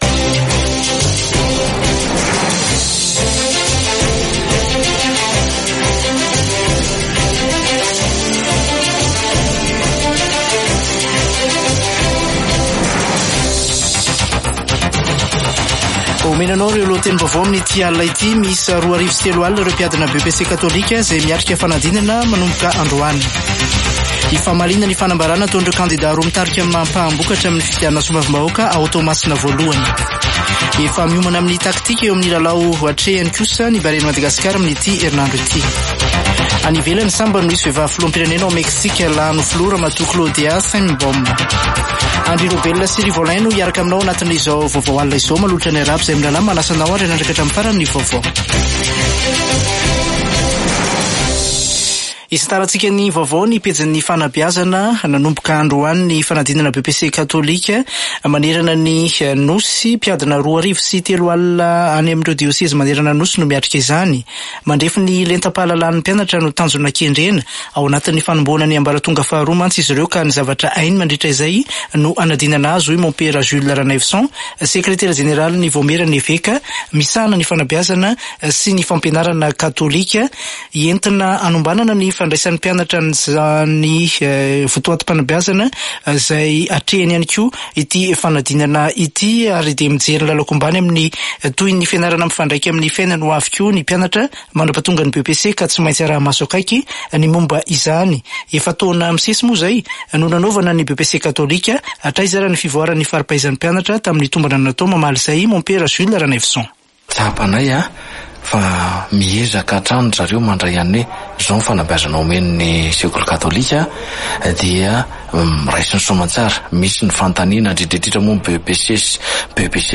[Vaovao hariva] Alatsinainy 3 jona 2024